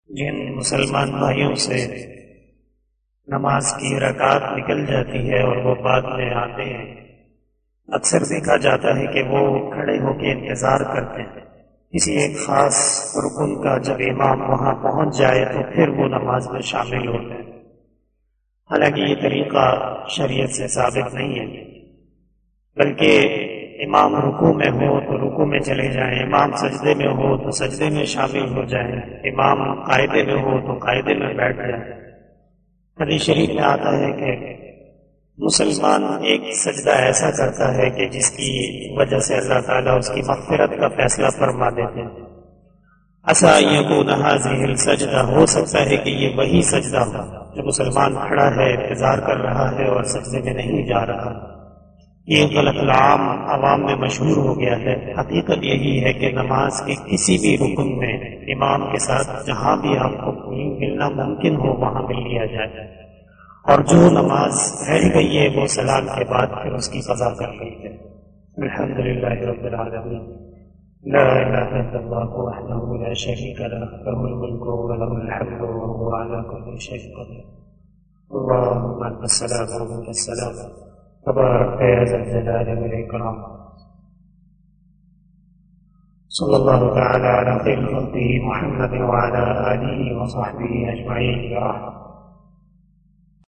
080 After Asar Namaz Bayan 13 November 2021 (07 Rabi us Sani 1443HJ) Saturday
بیان بعد نماز عصر